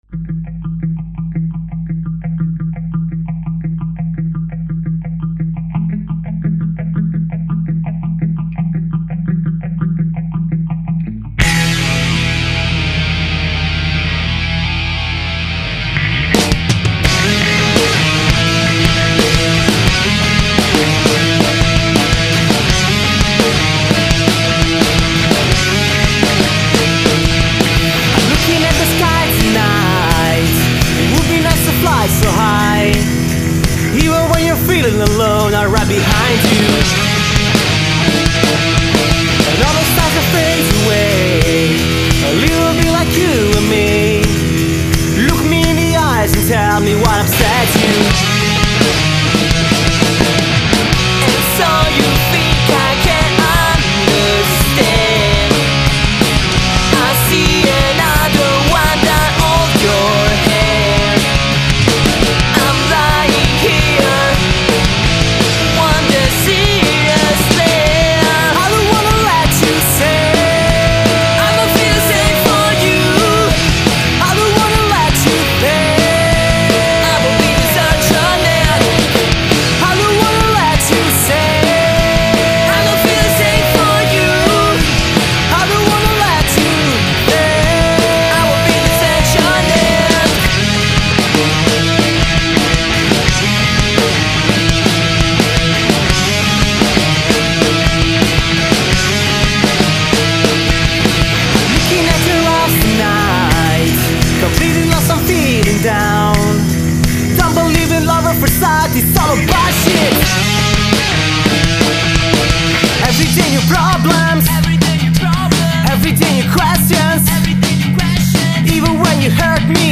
• Genre: Punkrock